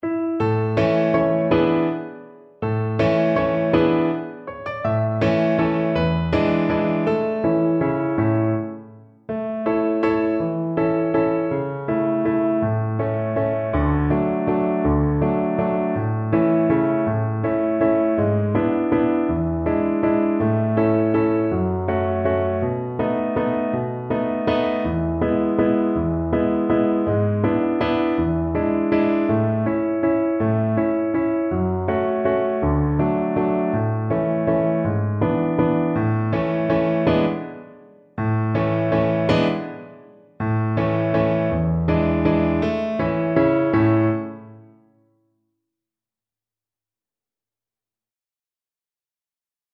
One in a bar . = c.54
3/4 (View more 3/4 Music)
Pop (View more Pop Violin Music)